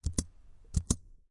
电气 " 电气计算机噪音
描述：很多电气噪音。我用我的笔记本电脑的AUX电缆到我的调音台的3.5毫米到5.25毫米的破损适配器听到了这个声音。我的手指在笔记本电脑的触控板周围擦拭并点击键盘上的按键产生噪音。我的空调也产生恒定的噪音。每隔几分钟就有一个整洁的小故障声音，我猜这是由于笔记本电脑上处理的一些数据。